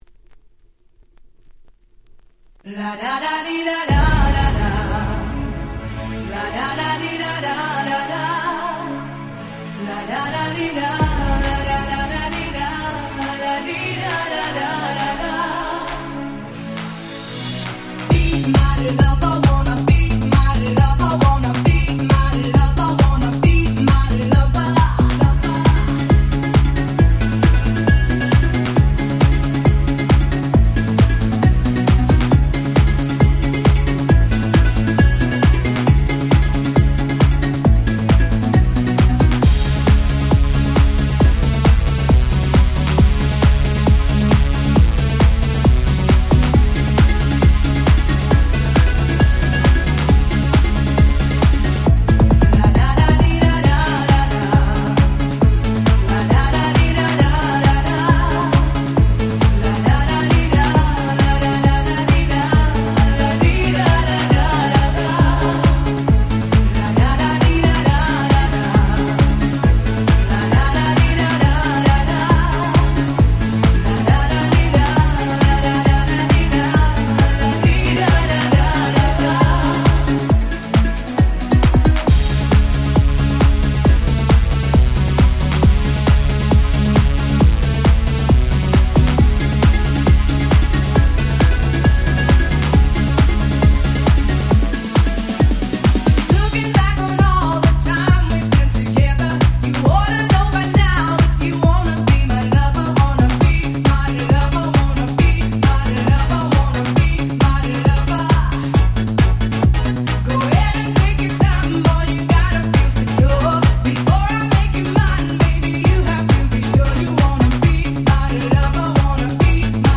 95' Super Hit Dance Pop !!
「ラダダディダラ〜ダダ〜」のキャッチーなサビで大ヒット！！
ユーロダンス House ハウス キャッチー系